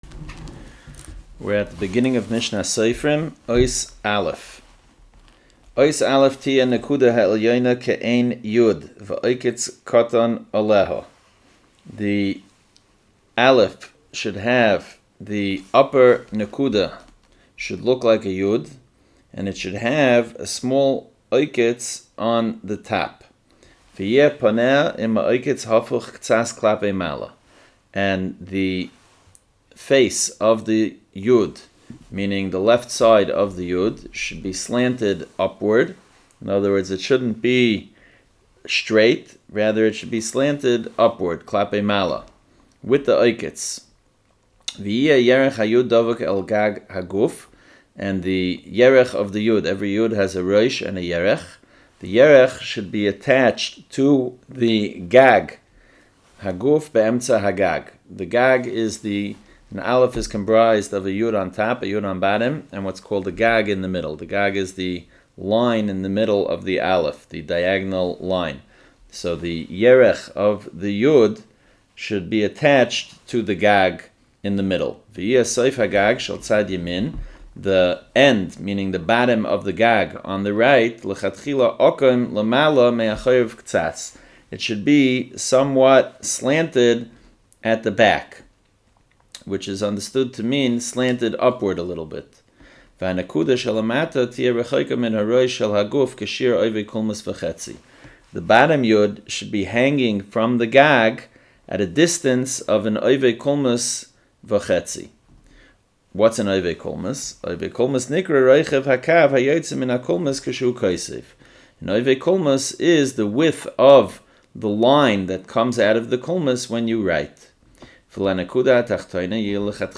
A free audio-based course covering the halachos and practical details of Hebrew letter formation in Sta”M. Learn the shapes, laws, and kavanos behind each letter—based on Mishna Berura and Keses HaSofer.